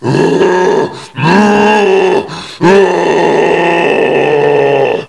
assets/nx/nzportable/nzp/sounds/zombie/r7.wav at 9a1952eb13f6e072cf1865de2f705dbe1bf677f1